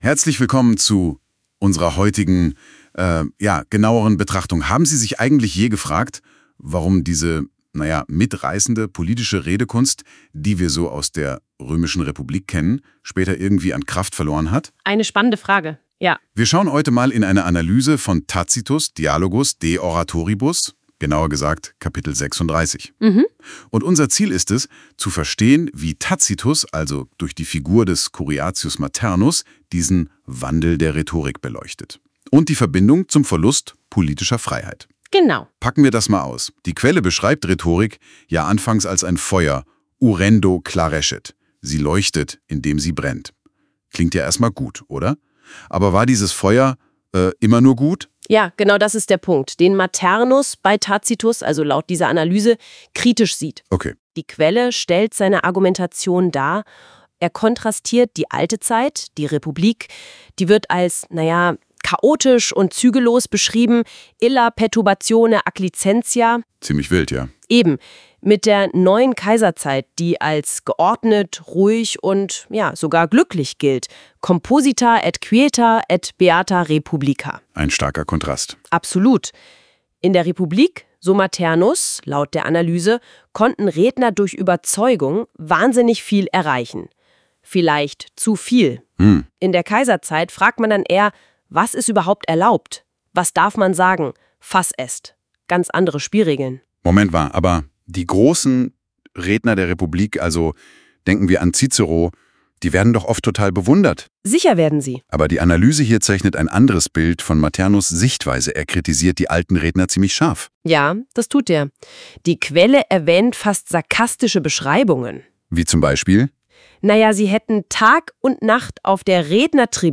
Die Auswahl der Originaltexte entstammt der Sammlung der alten Staatsexamenklausuren. Erstellt mit Google Notebook LM